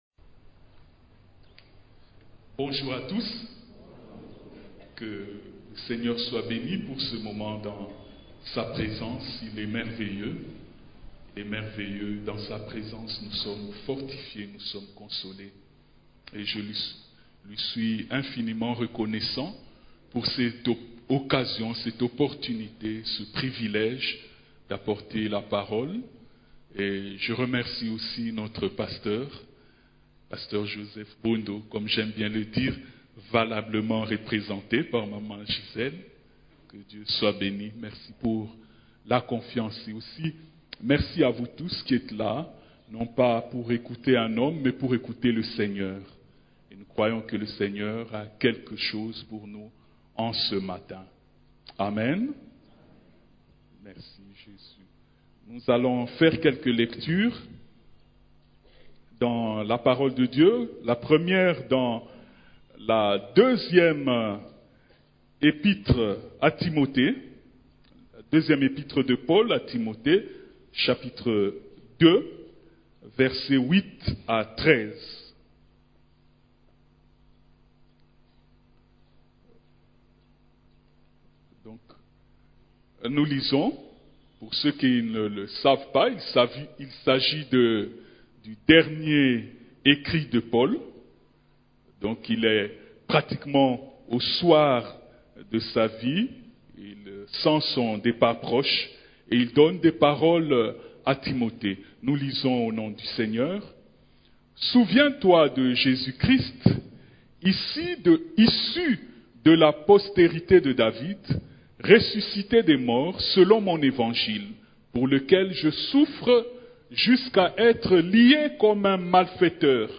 Culte du Dimanche